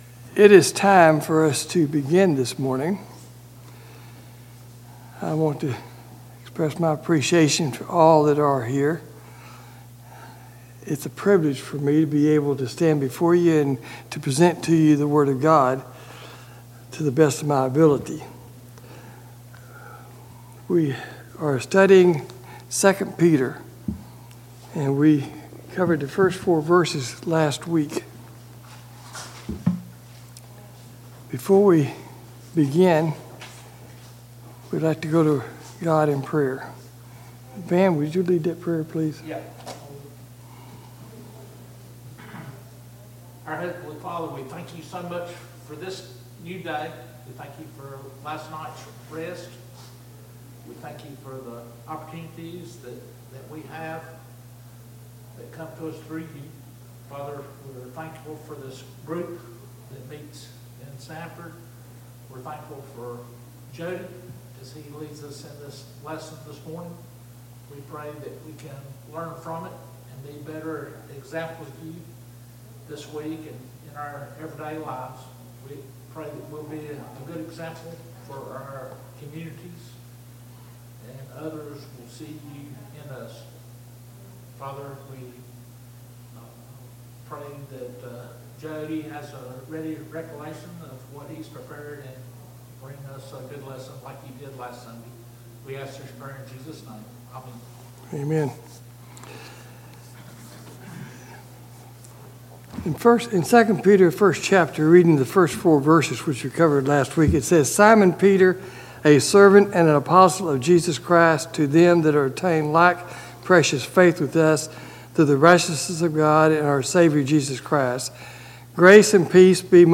Bible Class: II Peter 1:1 – 7 | Sanford church of Christ
Service Type: Sunday Morning Bible Class